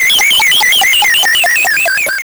alien.wav